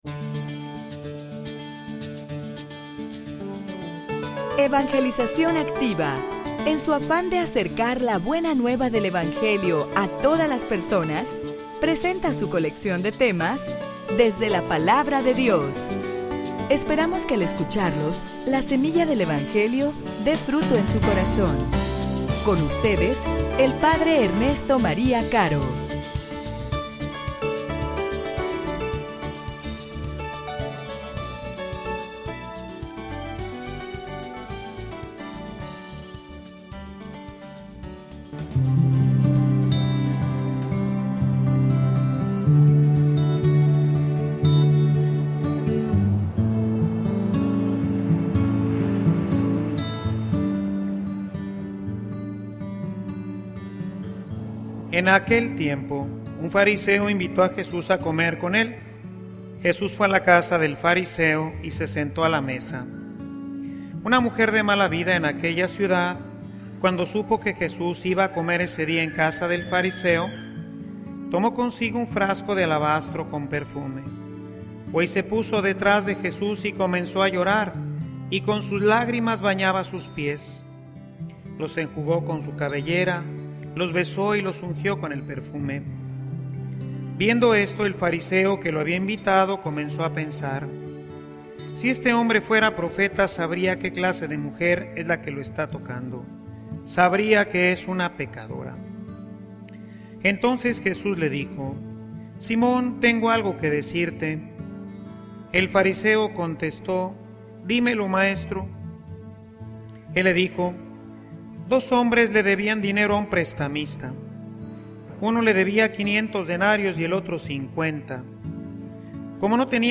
homilia_La_reconciliacion_un_proceso_de_amor.mp3